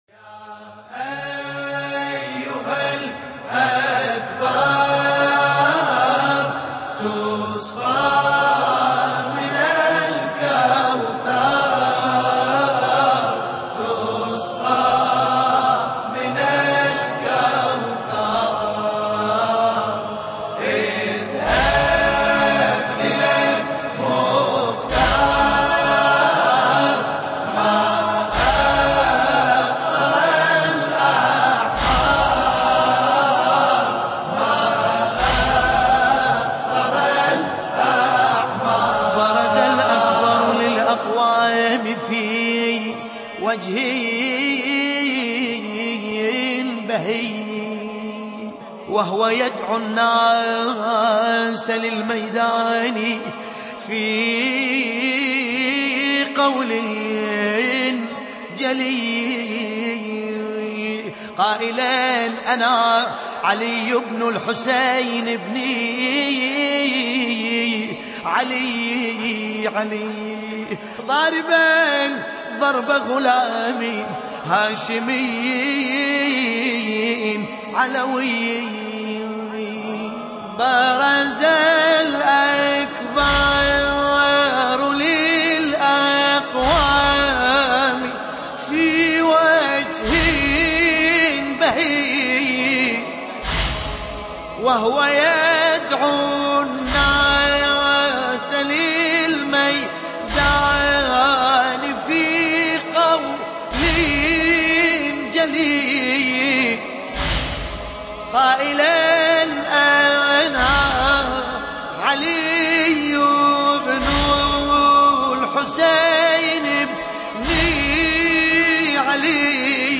قصيدة